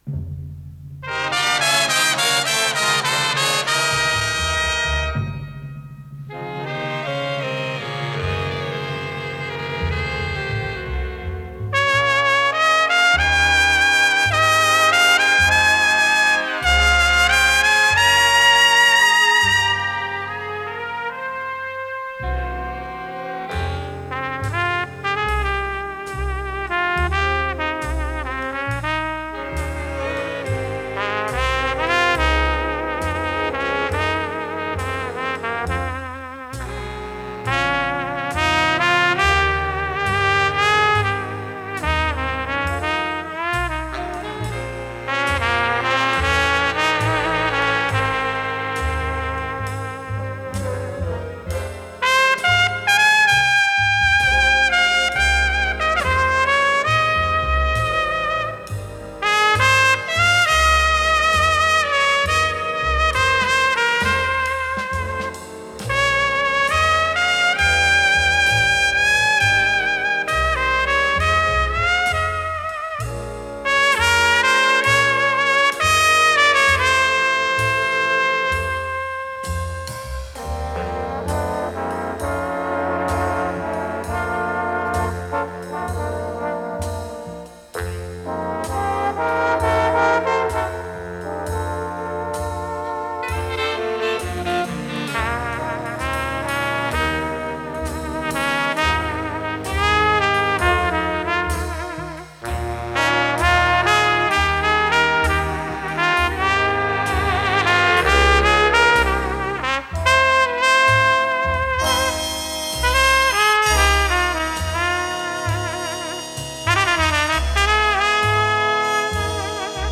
с профессиональной магнитной ленты
труба
ВариантДубль моно